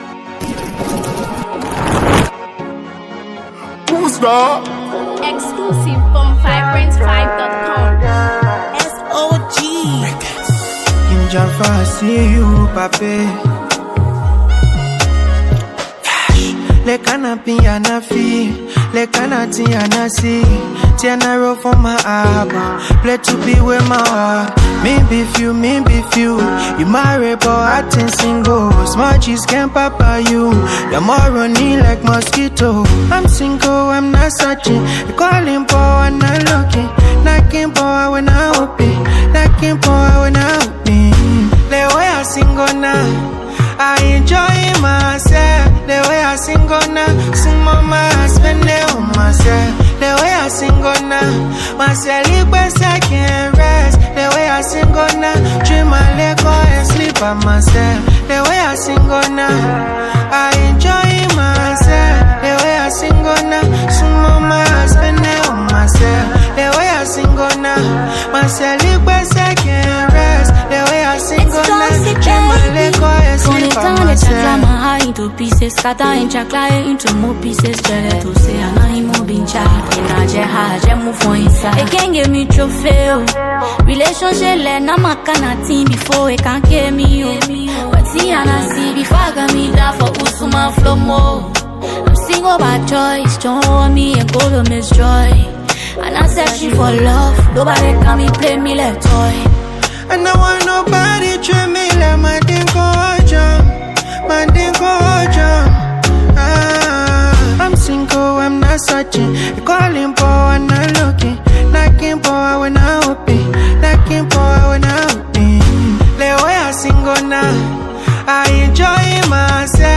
Liberian songstress
its sleek production and crisp arrangement
soaring vocal power